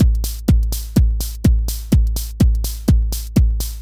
Index of /musicradar/retro-house-samples/Drum Loops
Beat 21 Full (125BPM).wav